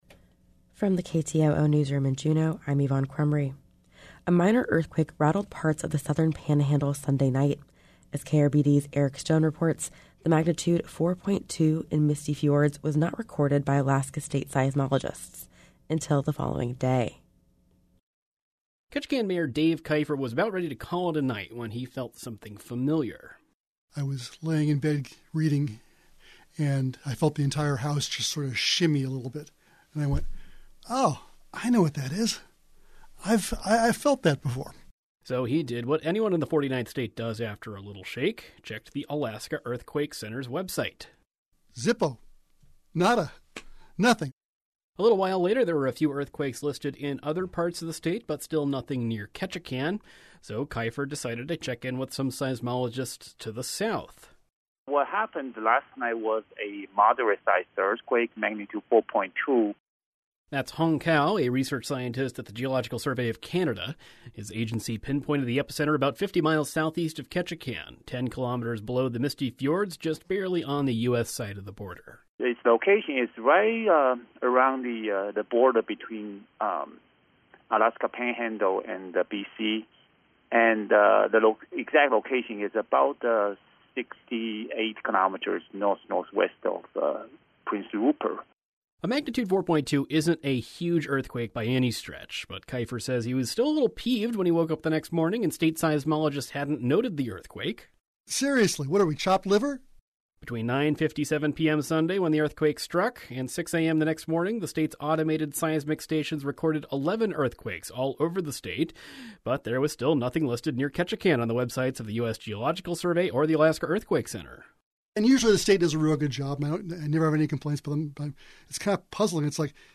Newscast – Tuesday, Oct. 11, 2022